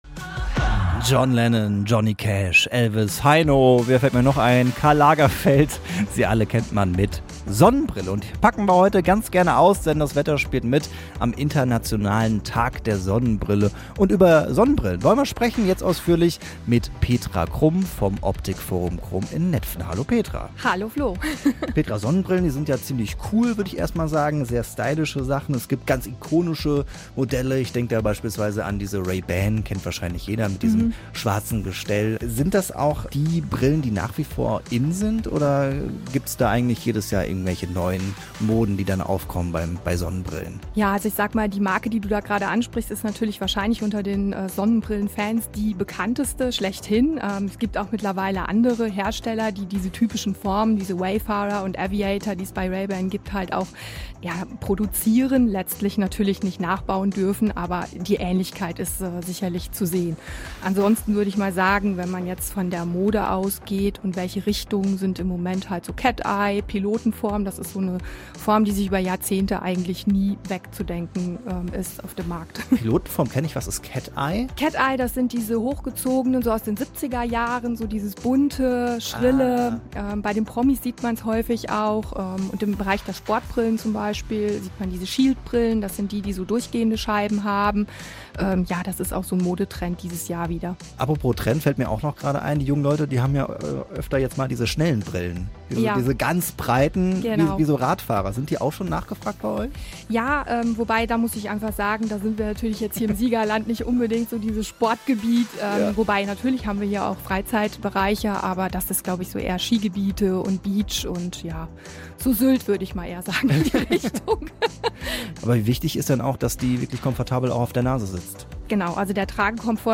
Tag der Sonnenbrille: Interview